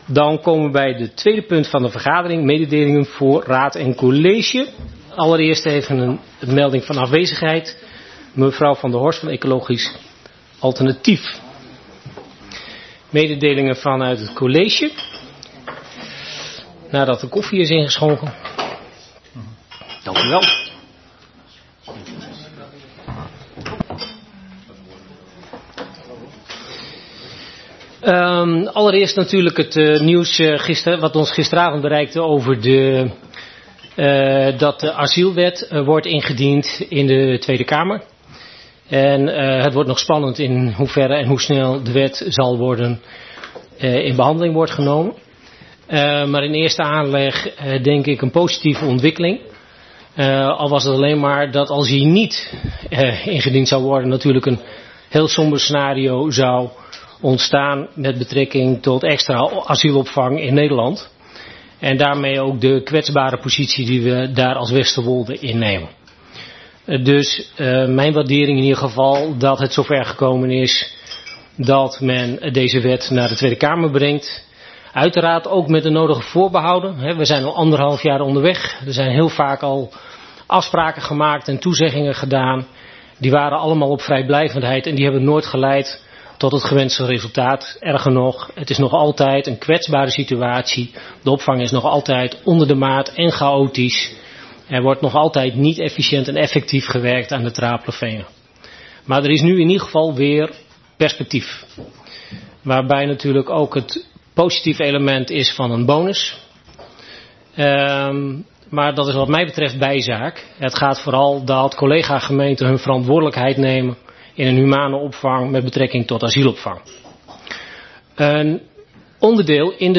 Geluidsverslag raadsvergadering 9 november 2022